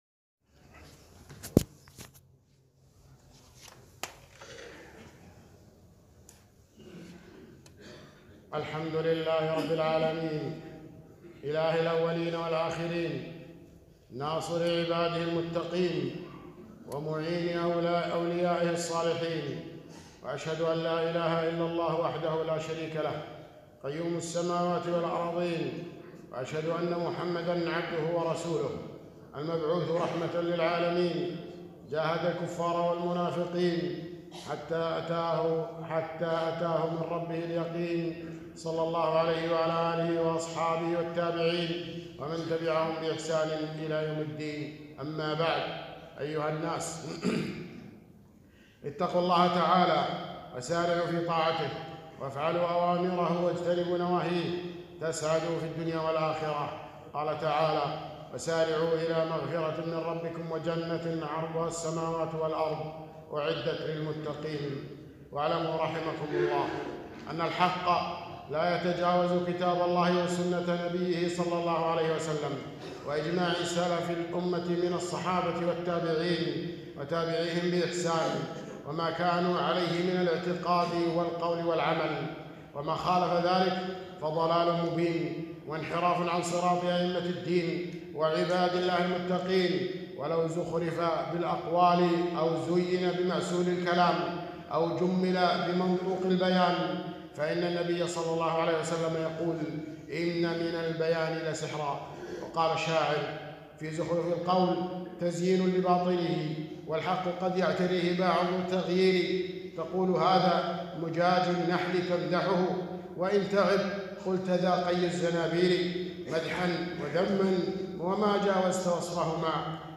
خطبة - قلب الحقائق والتمويه على الناس